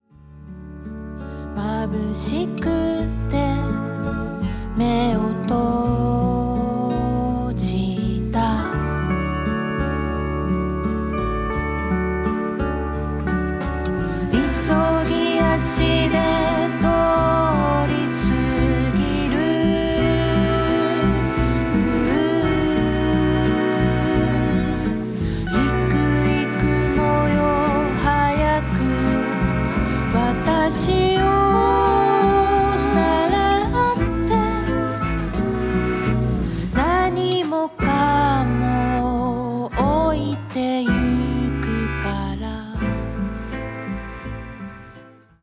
震える子猫を抱くような歌声。
スティール・ギターやサックス、リコーダーにヴィブラフォンも加わった７人体制のバンドサウンドはほんのりポップな装い。